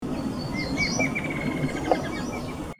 Rufous-margined Antwren (Herpsilochmus rufimarginatus)
Life Stage: Adult
Location or protected area: Parque Nacional Iguazú
Detailed location: Sendero Macuco
Condition: Wild
Certainty: Recorded vocal